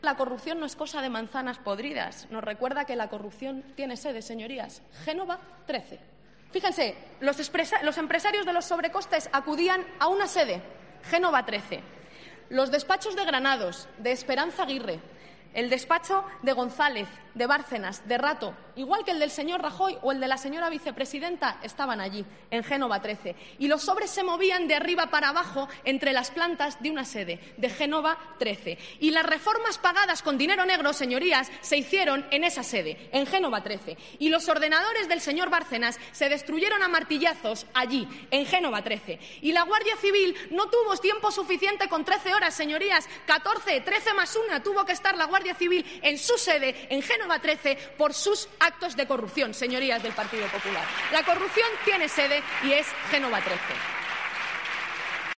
En su intervención en el pleno del Congreso para defender la moción de censura de su grupo parlamentario contra el Gobierno de Mariano Rajoy, Montero ha acusado también al Gobierno de "autoritarismo", de creerse los "dueños del cortijo", de "elitismo", mediocridad y de tener un proyecto "servil a las élites económicas".